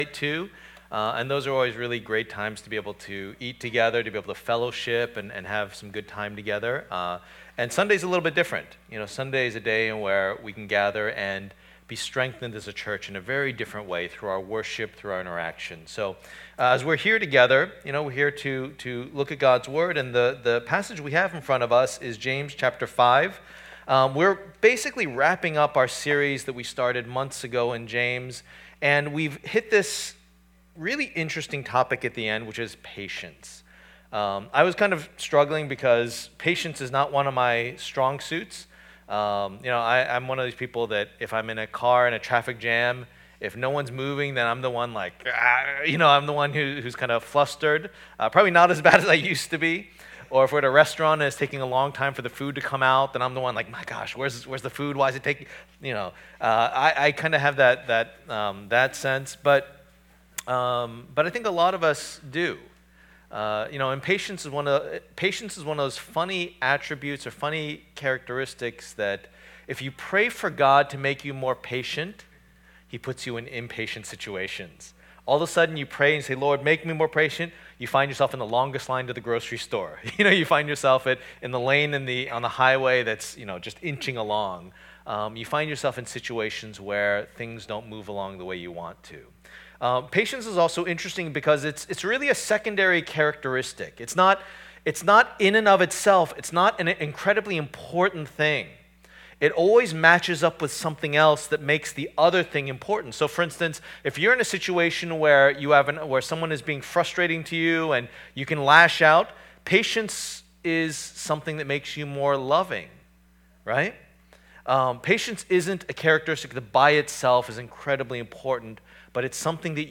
Passage: James 5:7-12 Service Type: Lord's Day